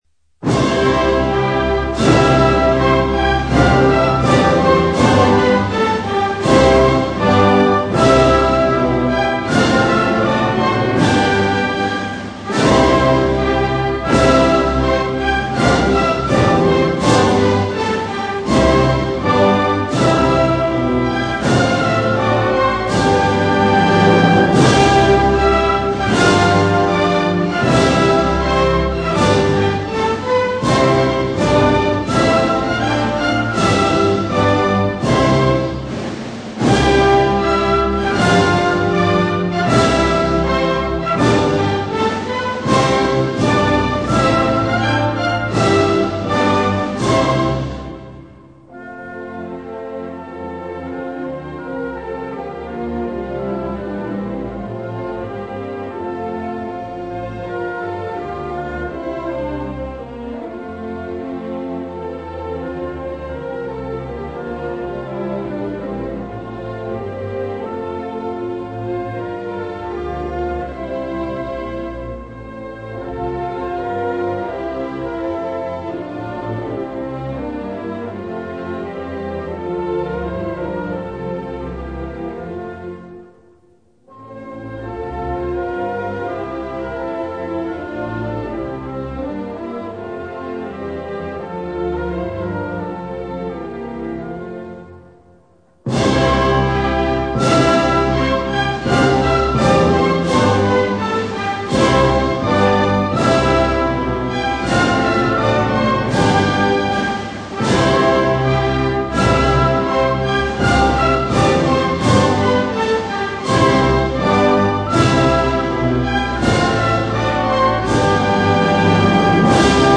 c) Himno.
El Himno nacional tiene su origen en un toque o marcha militar llamada Marcha Granadera, cuya primera mención documental data de 1749, siendo su primer manuscrito, fechado en 1761, encargado al maestro Manuel de Espinosa por el ayudante general de Infantería y caballero de Santiago, Martín Álvarez de Sotomayor.
Himno_Nacional.mp3